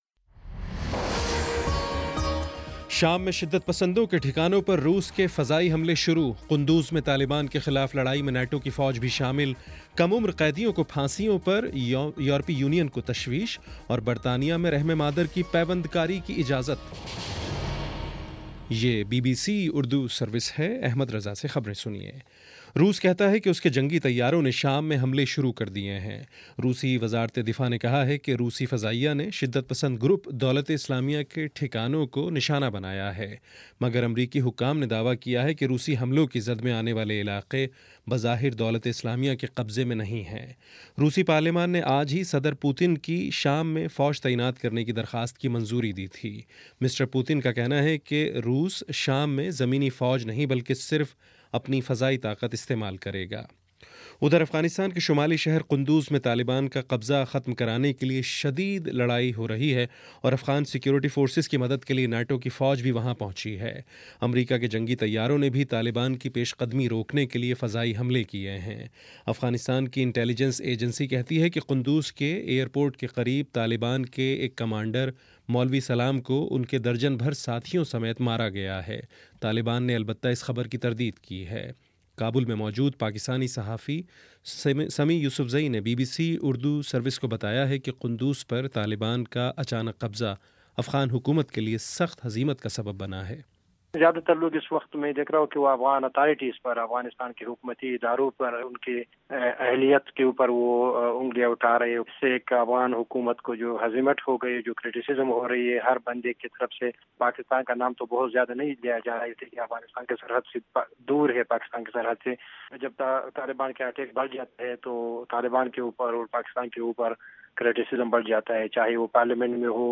ستمبر30 : شام سات بجے کا نیوز بُلیٹن